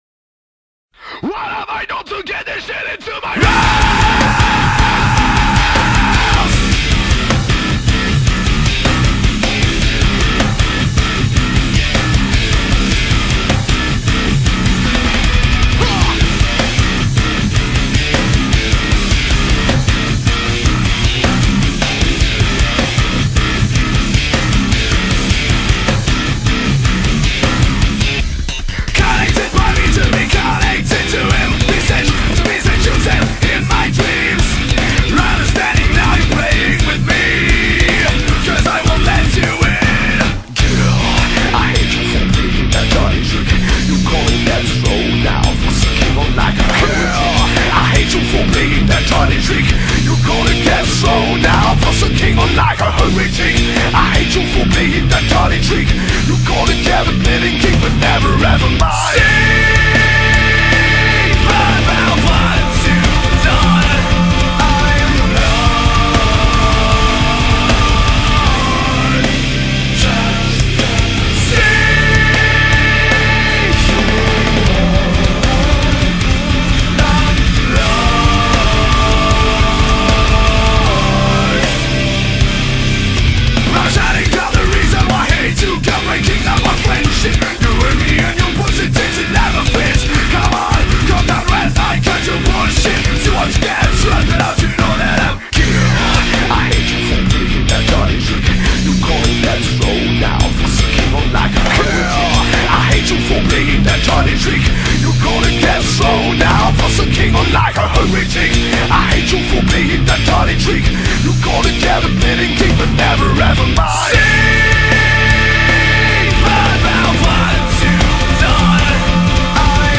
Emmené par un chant en Anglais puissant et juste